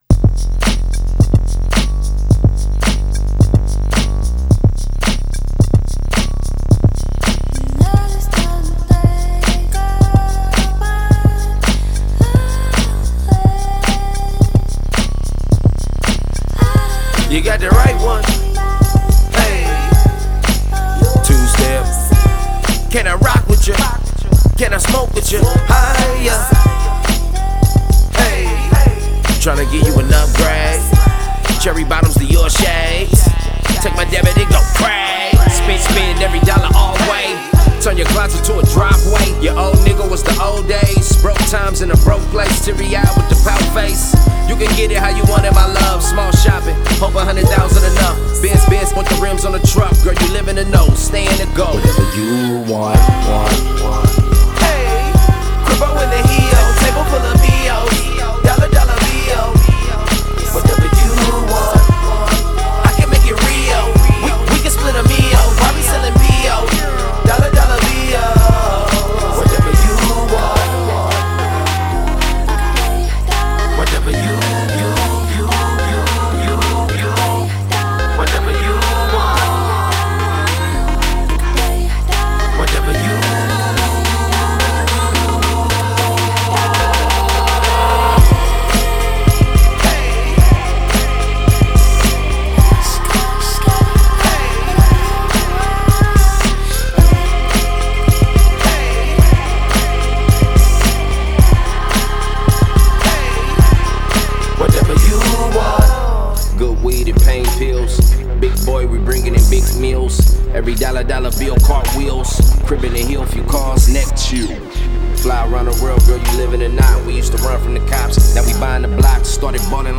3. EDM BASED
The bass, percussion, and synths are all on point.